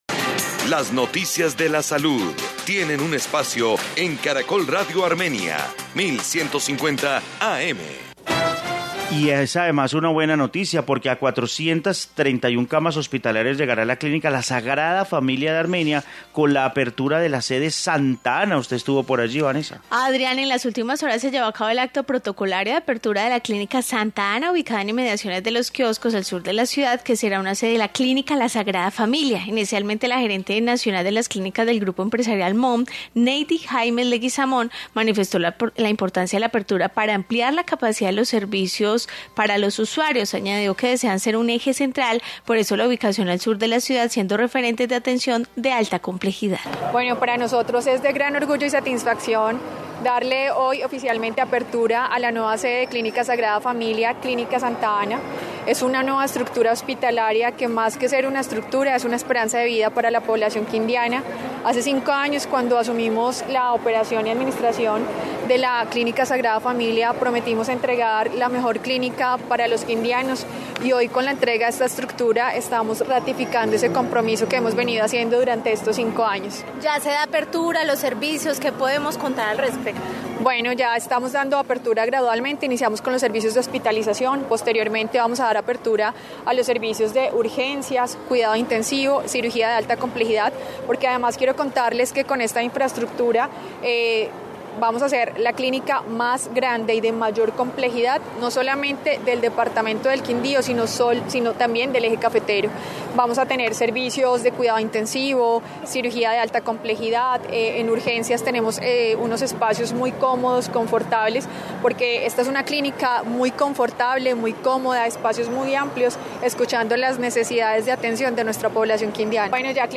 Informe clínica Santa Ana de Armenia